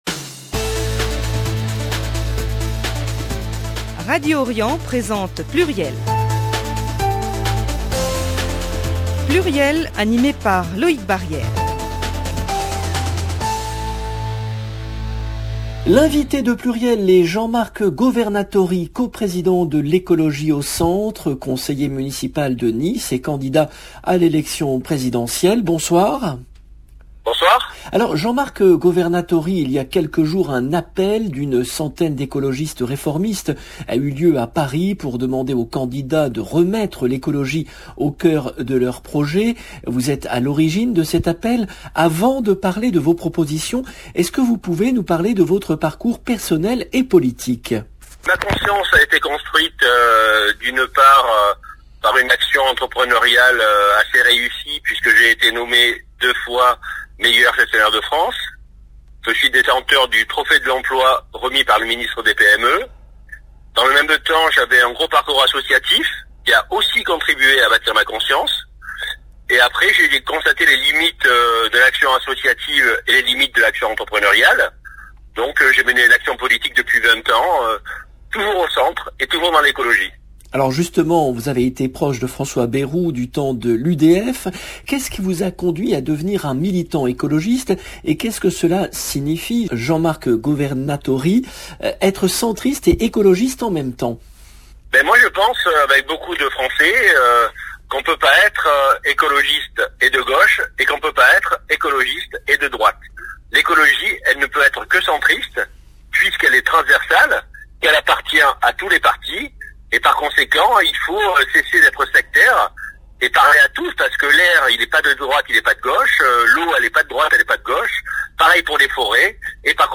PLURIEL, le rendez-vous politique du mercredi 23 février 2022